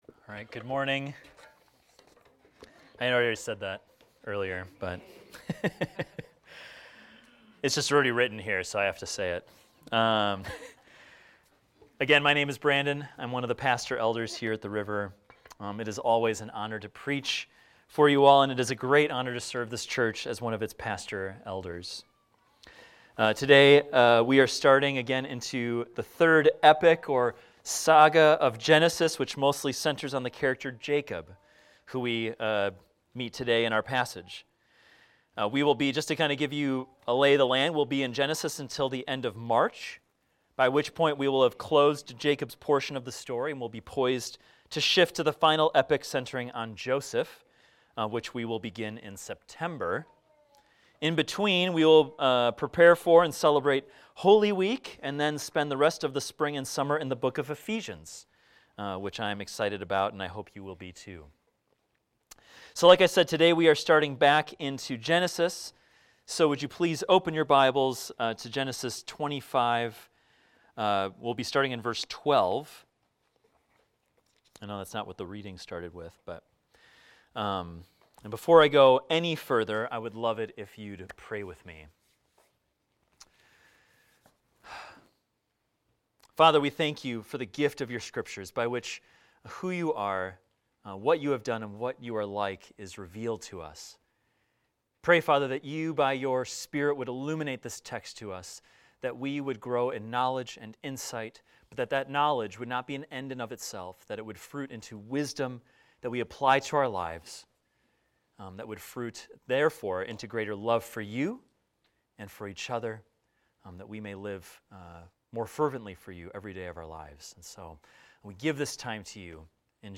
This is a recording of a sermon titled, "A Divided Household."